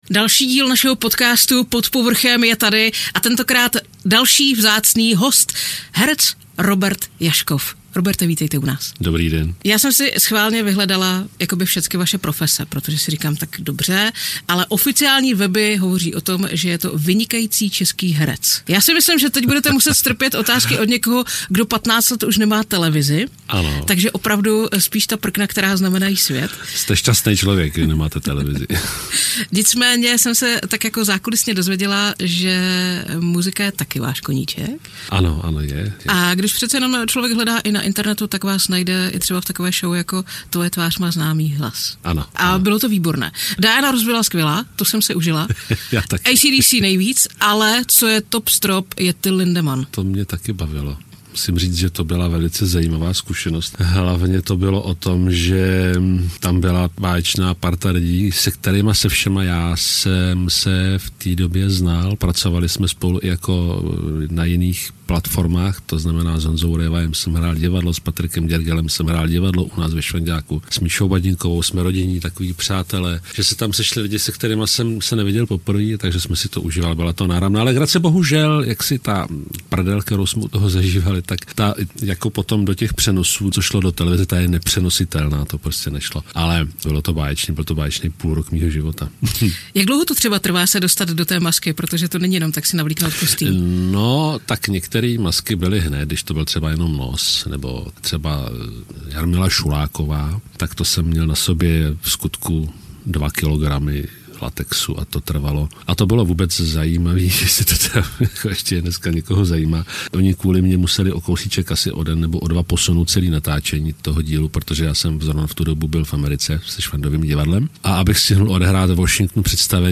Pod Povrchem - Charismatický herec, s pronikavým hlasem - Robert Jašków
Poslechněte si inspirativní rozhovor o naději, síle a empatii, která hýbe světem.